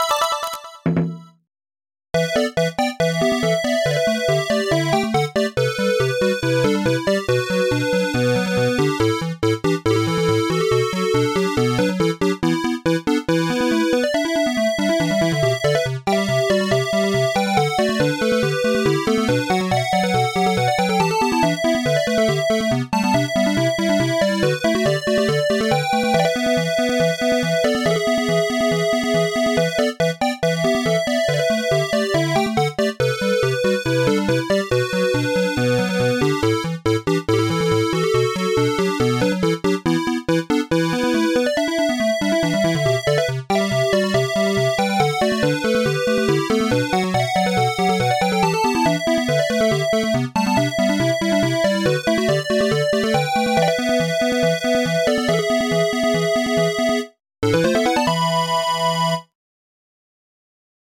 MIDI 7.03 KB MP3
8-Bit/Chiptune cover of the racing theme